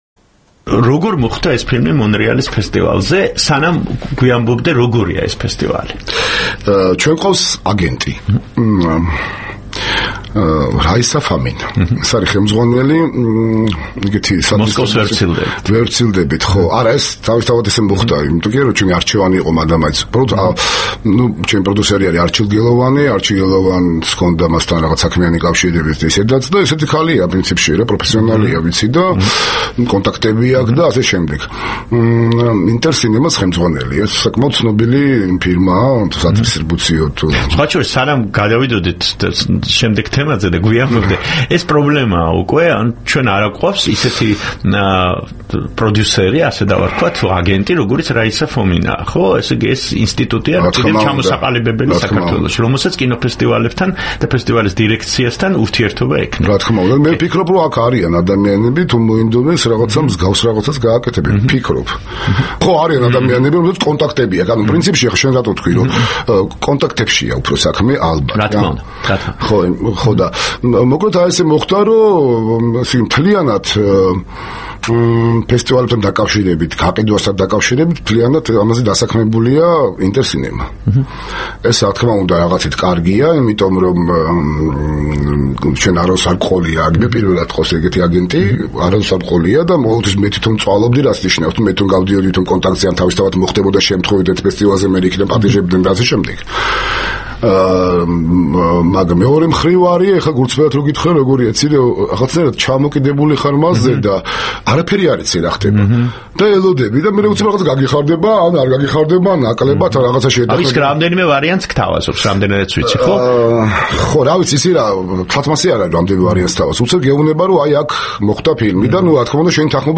ზაზა ურუშაძეს თბილისში დაბრუნებისთანავე გაესაუბრა.